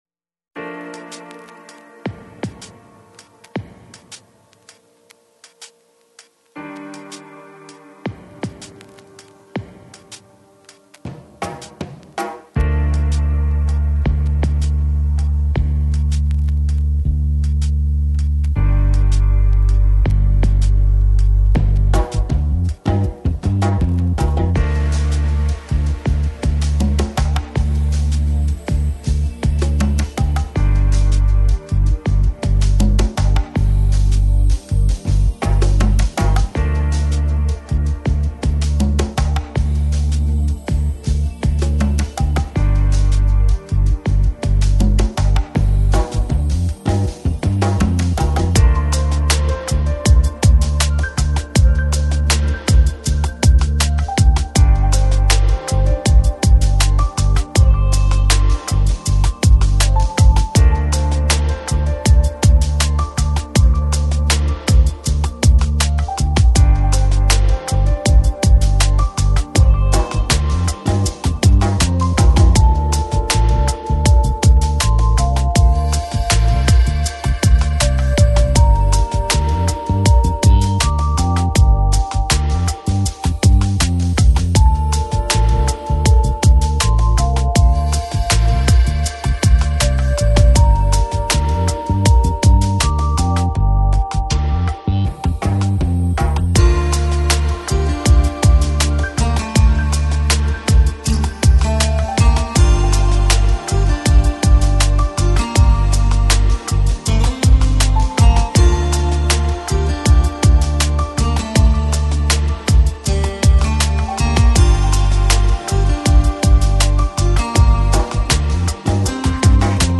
Chill Out, Lounge, Downtempo